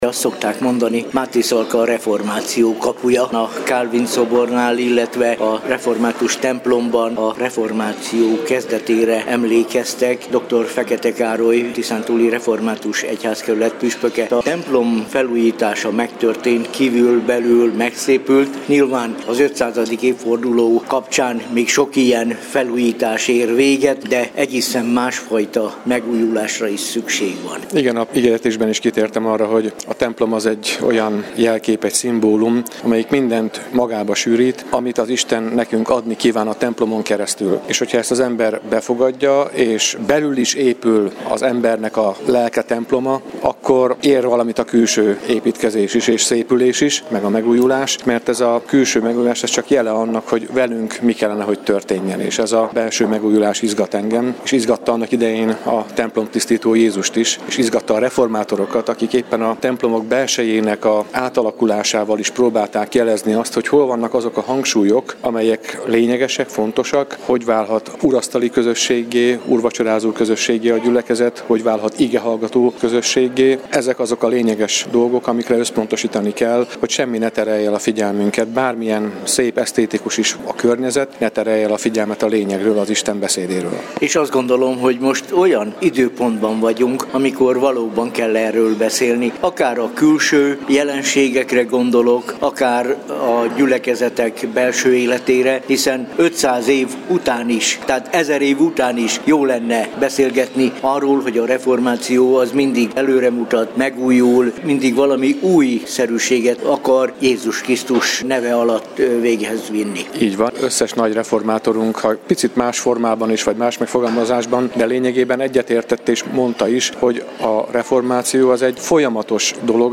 Az ünnepség Kálvin János szobránál kezdődött, majd hálaadó istentisztelettel folytatódott a Kossuth téri templomban. Az igét dr. Fekete Károly, a Tiszántúli Református Egyházkerület püspöke hirdette.